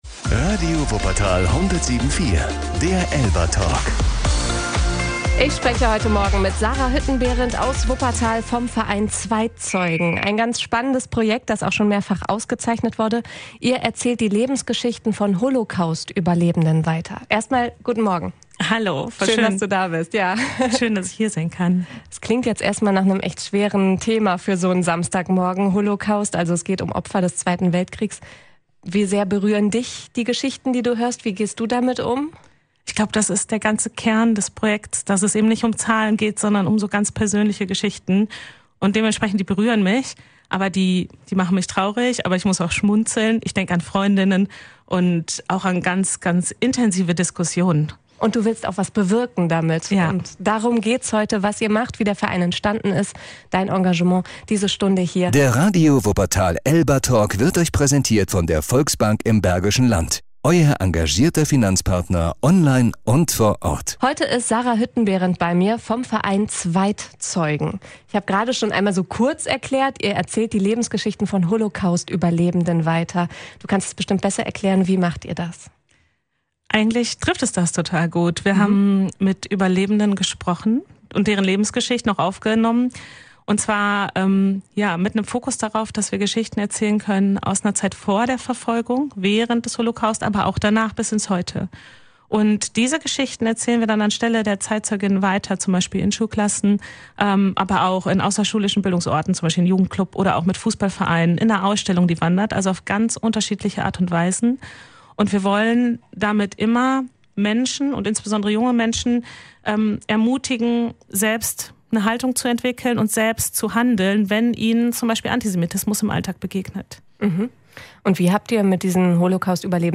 In diesem Interview geht es um Erinnerung, Verantwortung und Demokratie.